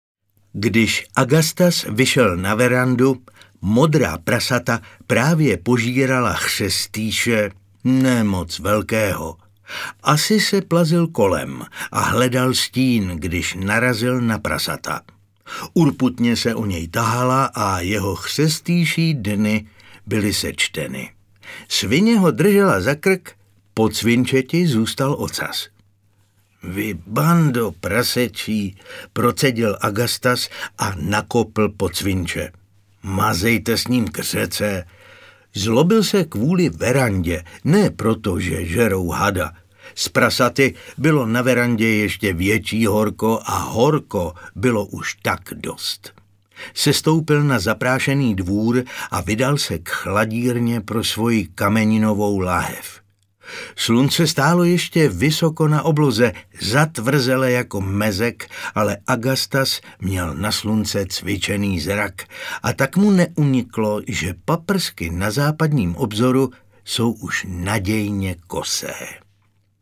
Osamělá holubice audiokniha
Ukázka z knihy